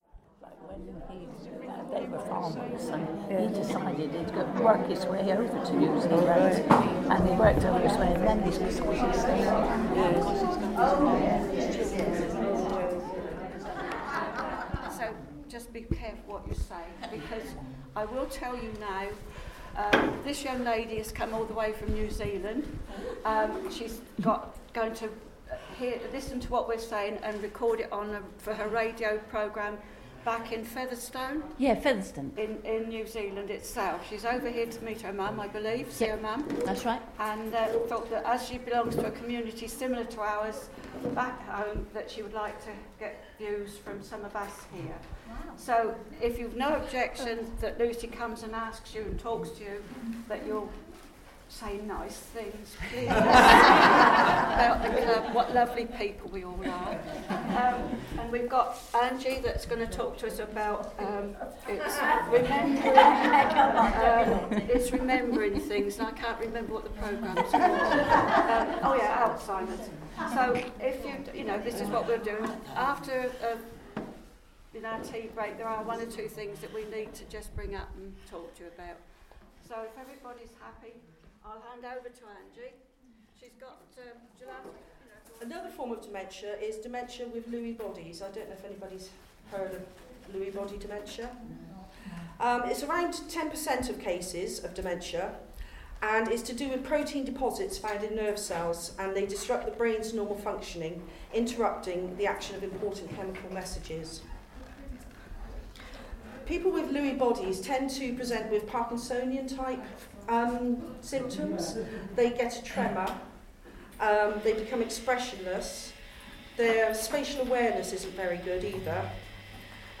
I had the privilege of recording July’s edition of Southern Exposure live(ish) and direct(ish) from Weymouth, Dorset, the other week for Arrow FM. Southern Exposure is the Featherston Community Centre’s monthly programme, bringing listeners news of what’s on at our Centre and in the wider Featherston and South Wairarapa Community.
In the best tradition of community centres, I was made very welcome, and had a great afternoon chatting away to some of the volunteers that keep the Park Community Centre not just going, but thriving.